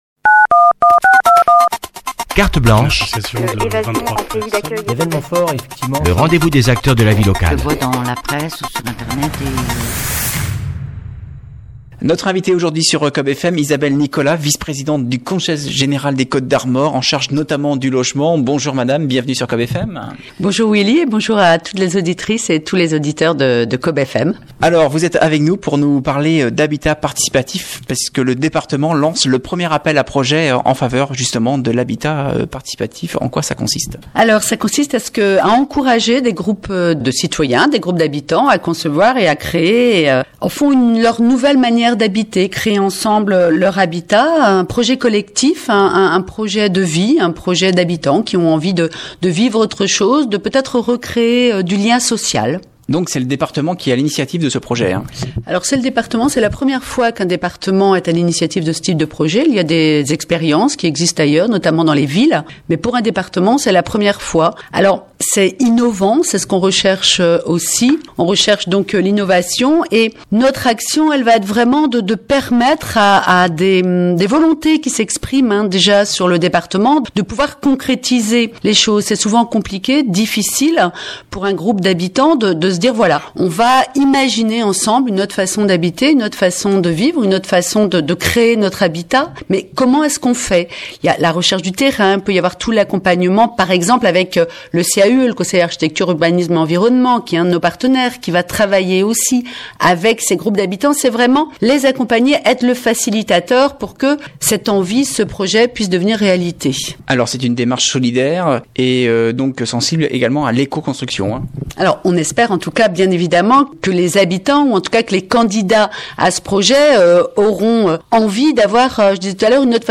Entretien avec Isabelle Nicolas, vice-présidente du Conseil général en charge notamment du Logement